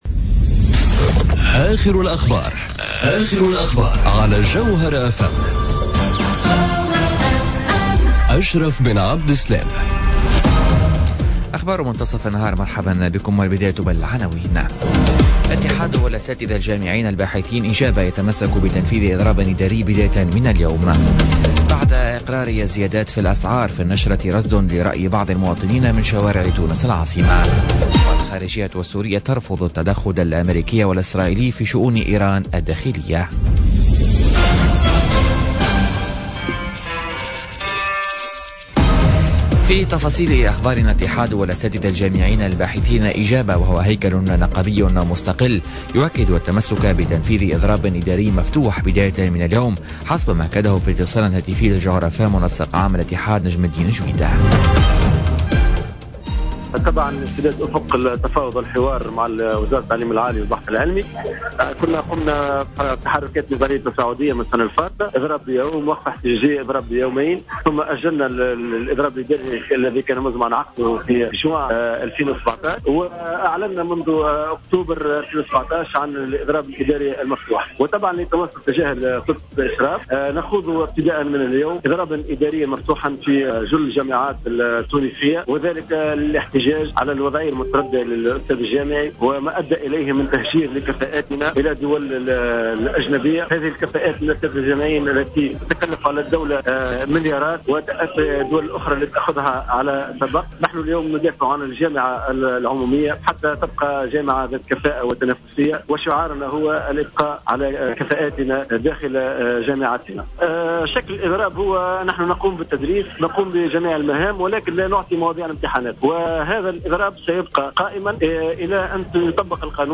نشرة أخبار منتصف النهار ليوم الثلاثاء 02 جانفي 2018